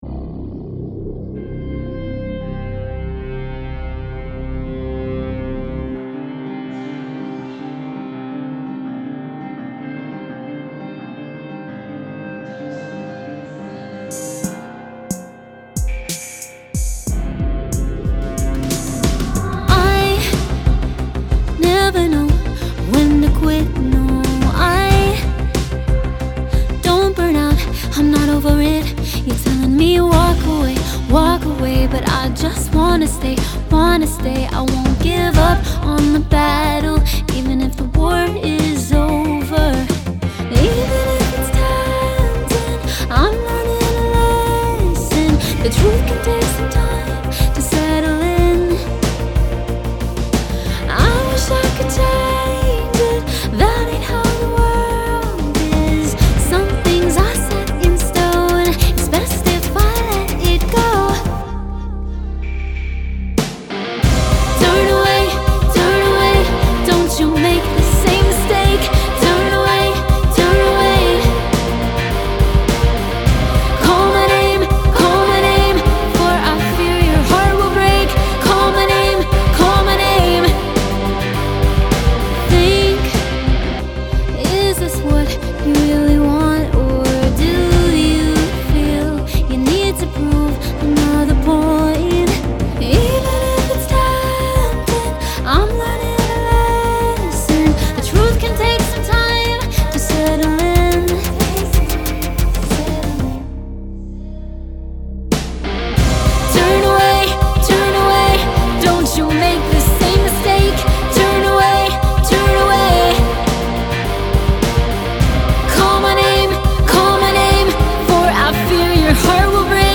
Sax solo